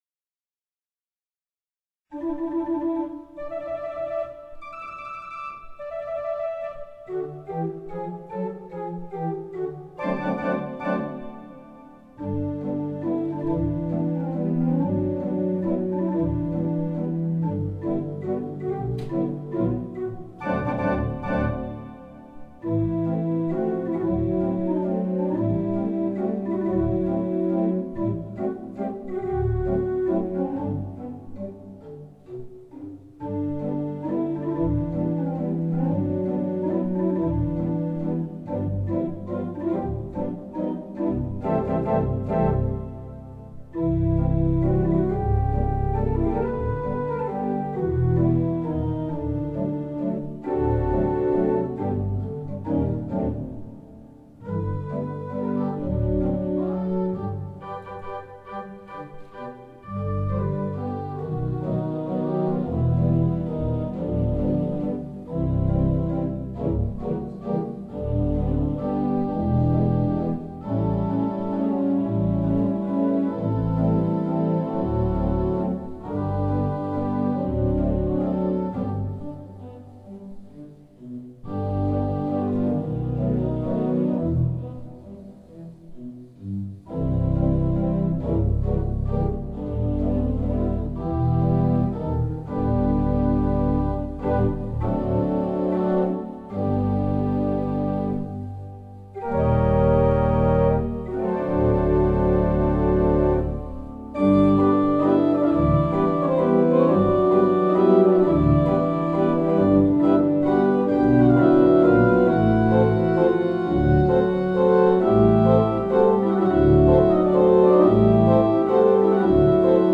Free music downloads from the 4/89 Mighty Digital 460S Church Organ at the All Faith Chapel in Ridgecrest, California
They are more akin to the kind of music one would hear from the Mighty WurliTzer Theatre Pipe Organ but played with a mountain gospel style.
In a couple of places, you will hear that unmistakable rumble of the mighty 64-footer.
The music we hear in the list of songs above was recorded live using a Samson Zoom H4 digital audio recorder, seen in the picture above.
To make the recordings, the Samson Zoom H4 digital audio recorder was mounted on the tripod and placed near the center of the congregational area about twelve rows back from the Holy Dias.